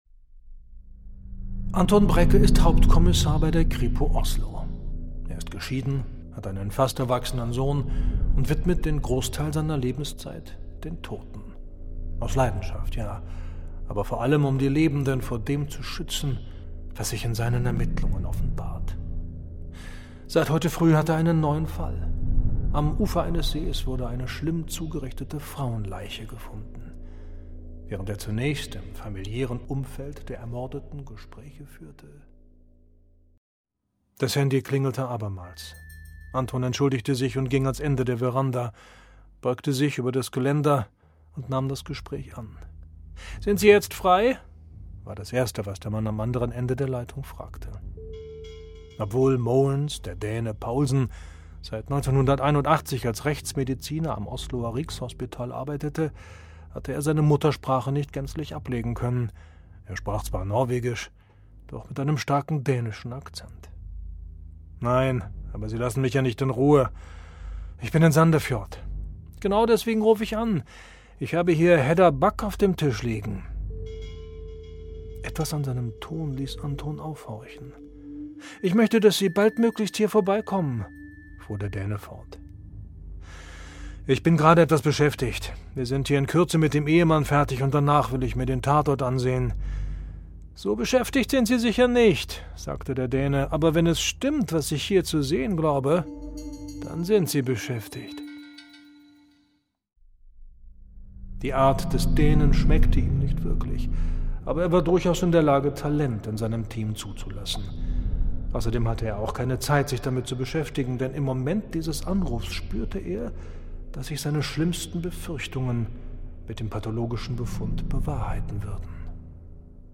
markant, sehr variabel
Mittel plus (35-65)
Audiobook (Hörbuch)